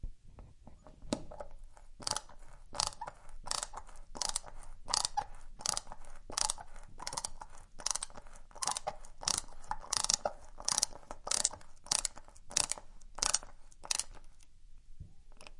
刮削金属
描述：钢尺和小螺丝刀相互刮擦，使其听起来像是尖锐的东西。 使用Zoom H6记录器，使用XY胶囊，在车库中。
Tag: 挥砍 锐化 金属 刮痧 OWI 钢铁